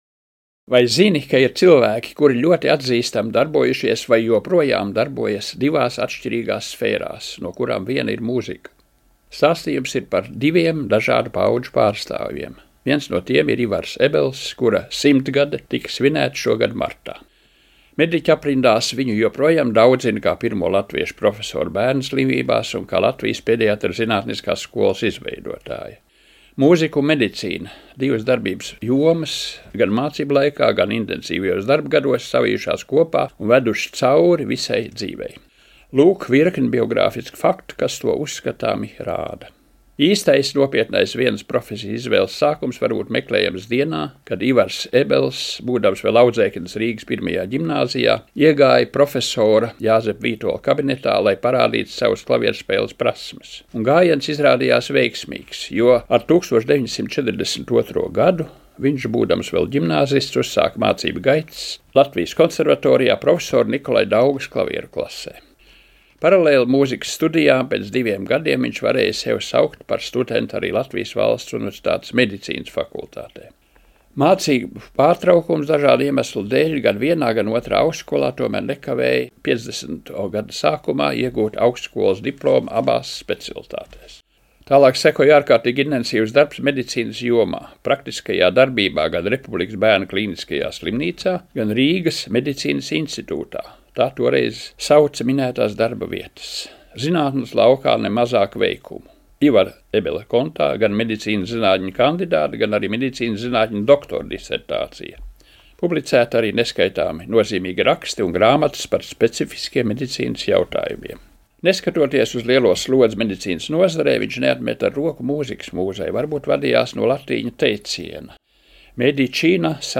Stāsta muzikoloģe